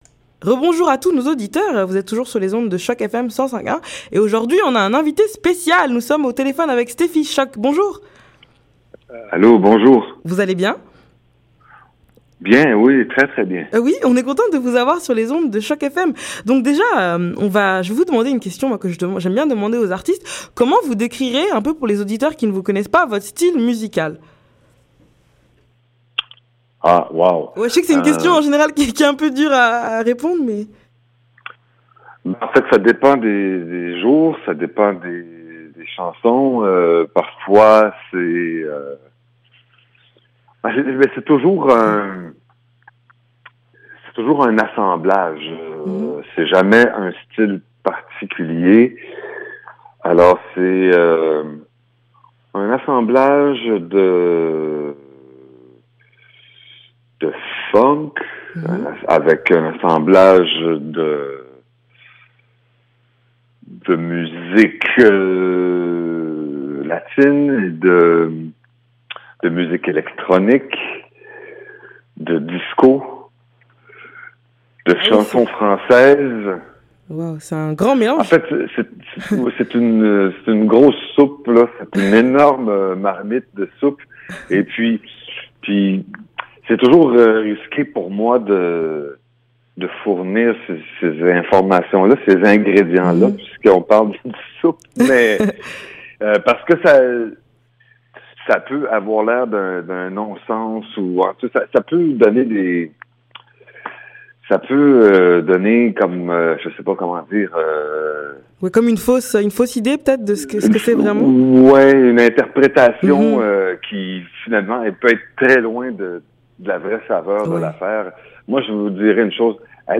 Entrevue avec Stefie Shock